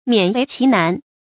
勉为其难 miǎn wéi qí nán
勉为其难发音
成语正音为，不能读作“wèi”。